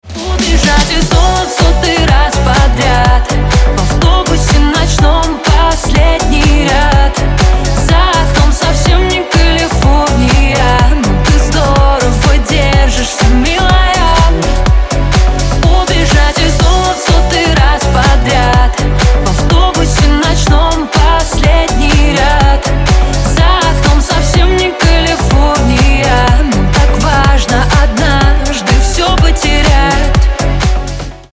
Стиль: club house